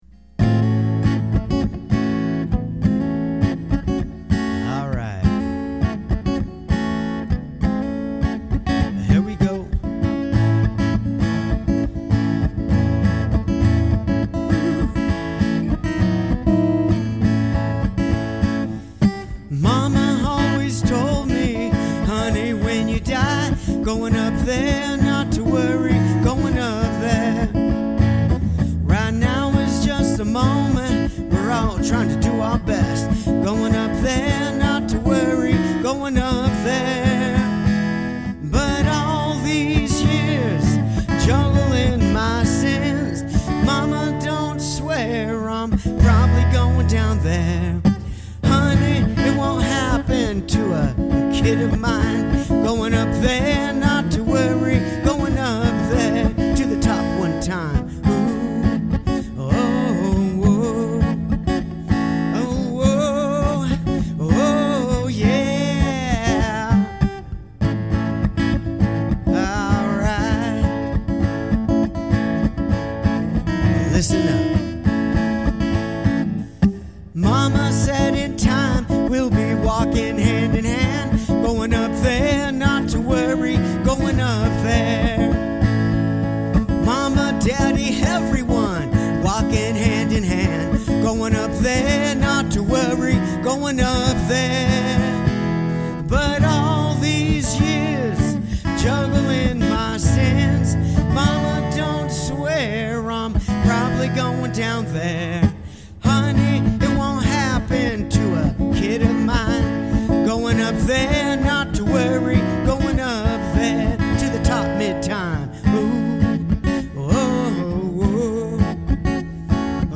This is a gospel-ish style song.